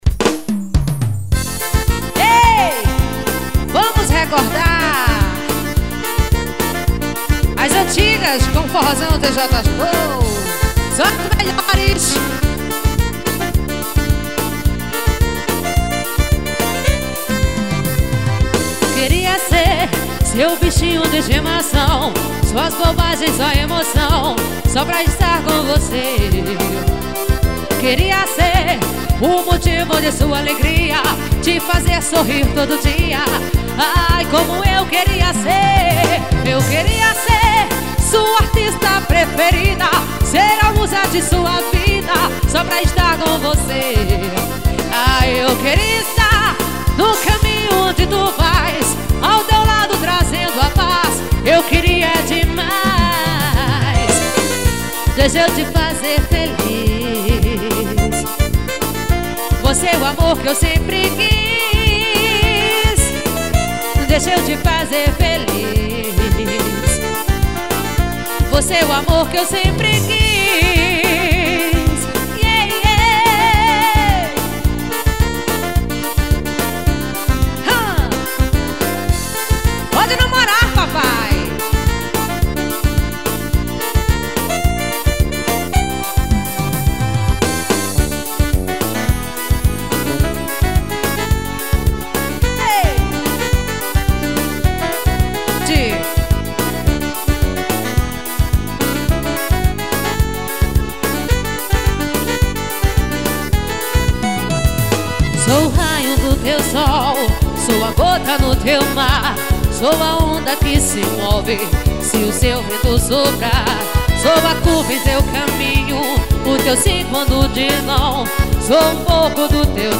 AO VIVO.